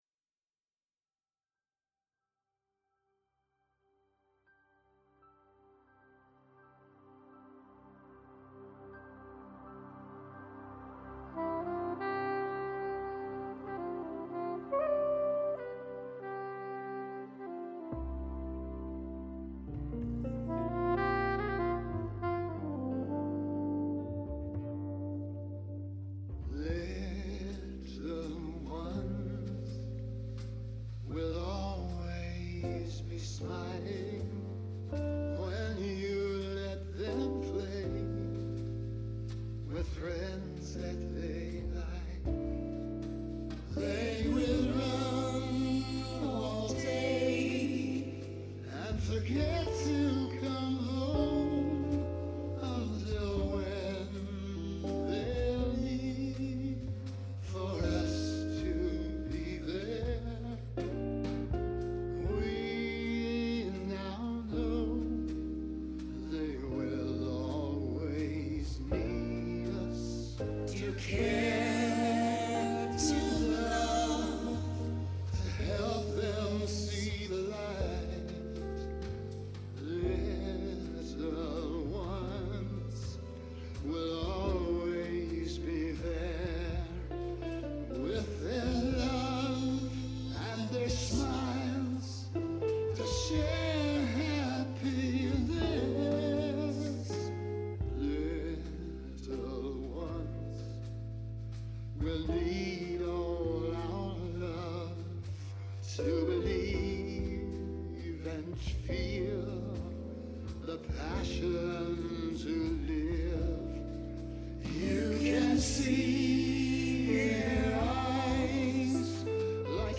chanteurs
guitariste
batteur